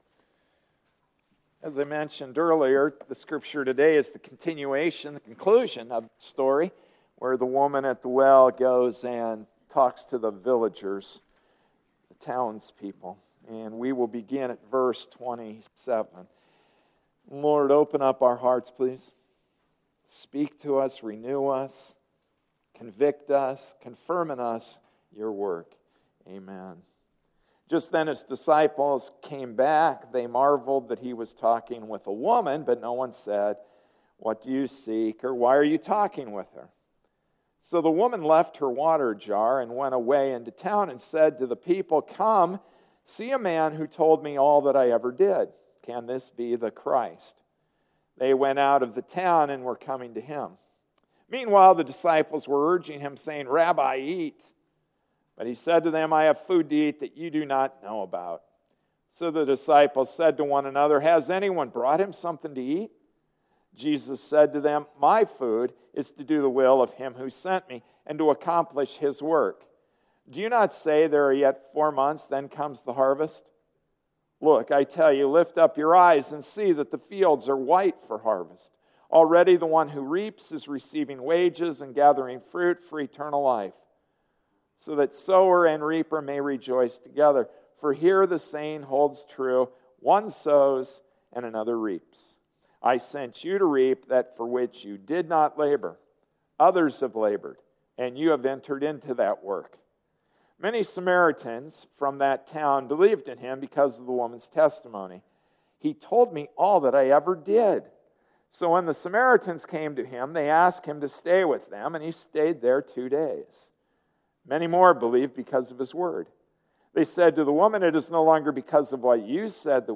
February 9, 2014 Waterfall Passage: John 4:28-42 Service Type: Sunday Morning Service “Waterfall,” John 4:28-42 Introduction: What is an evangelist? Which is more loving, to share your faith or to remain silent? Who is qualified for the job?